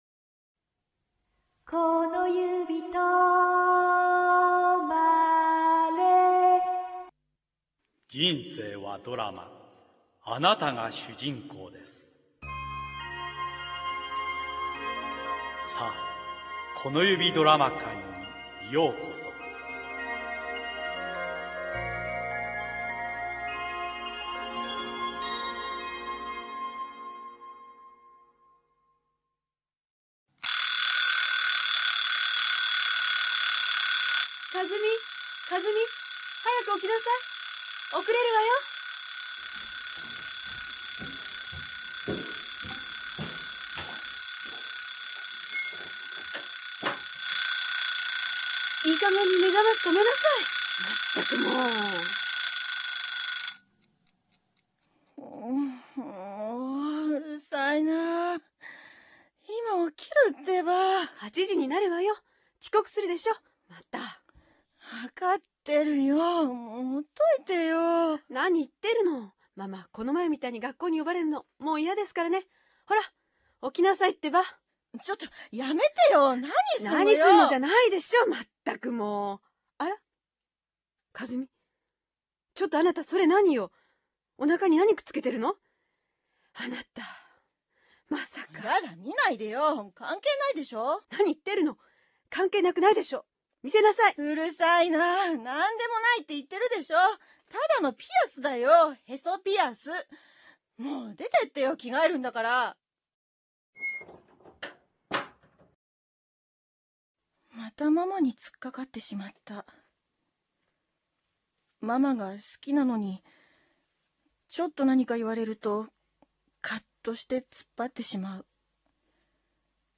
この指ドラマ館 テーマ〈死と命〉 - ネット・ラジオドラマ「この指とまれ」